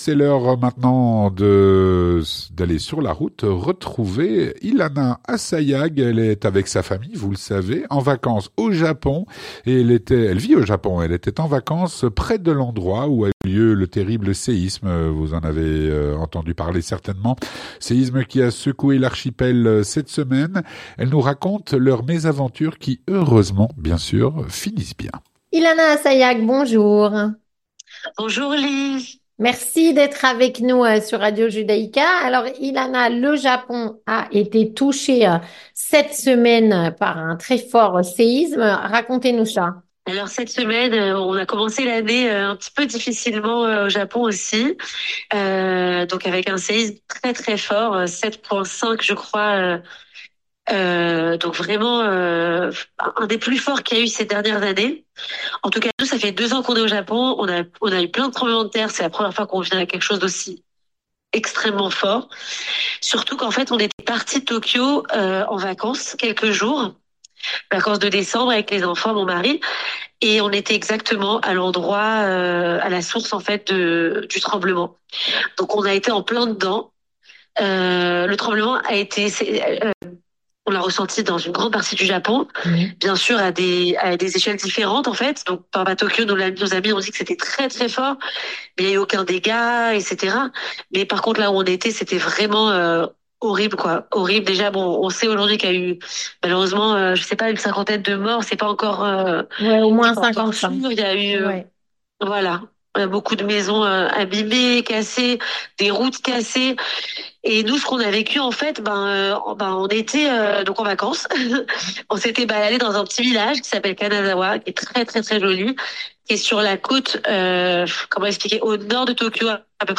Sur la route - Un séisme a secoué le Japon en début de semaine. Témoignage.